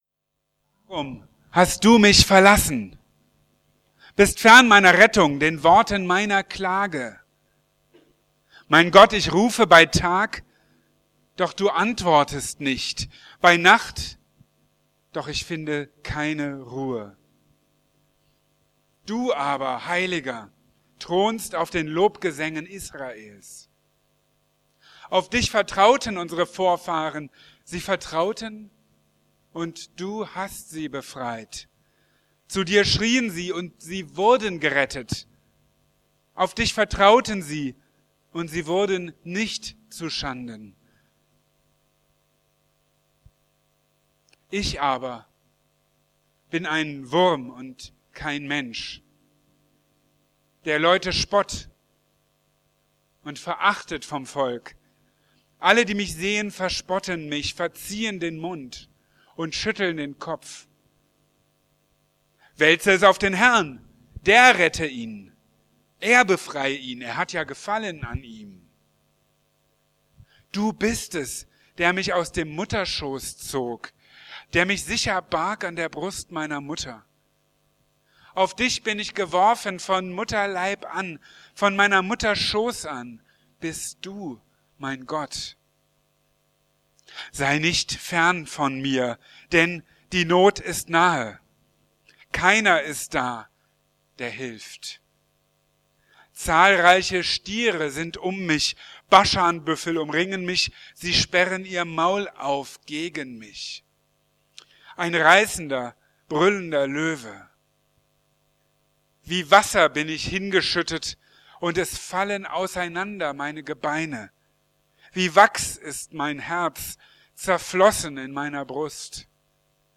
Karfreitagspredigt vom 6. April 2012 .